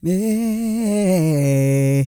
E-GOSPEL 217.wav